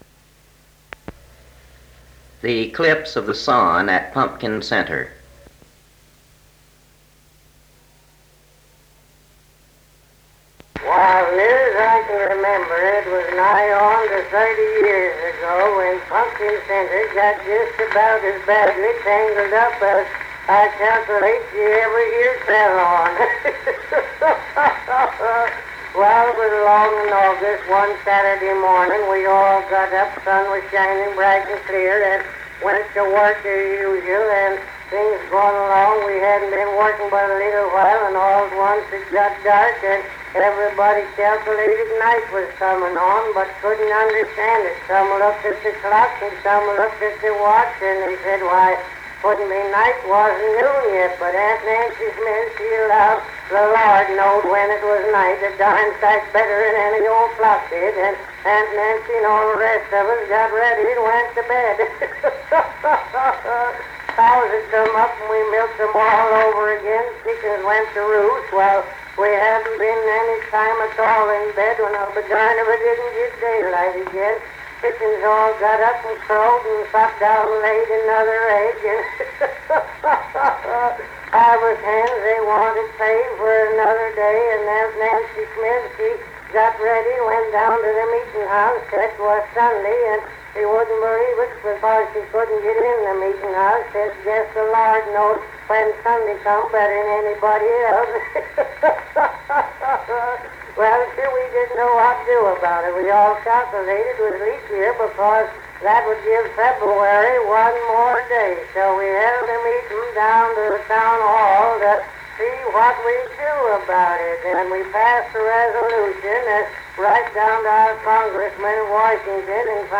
Cal Stewart's comedy routine, The eclipse of the sun at Punkin Center.